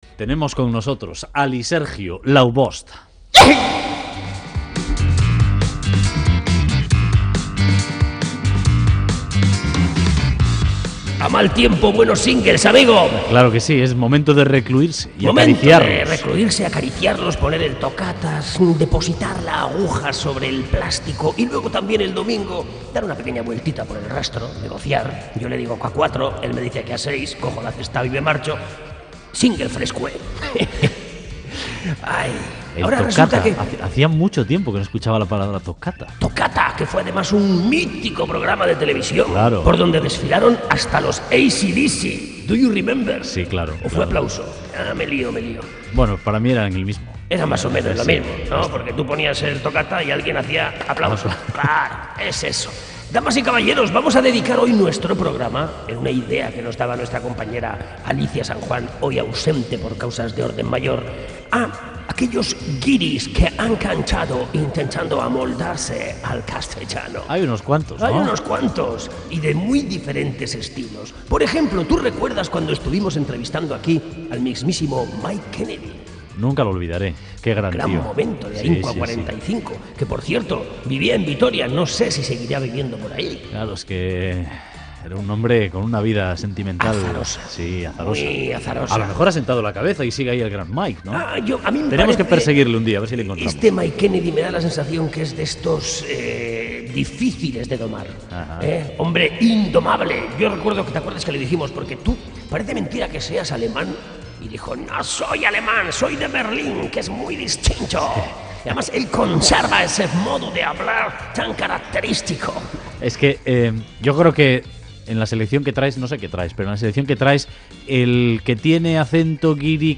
Constituyen una de las vetas más divertidas del mundo "single". Guiris que quieren ampliar mercado cantando en castellano...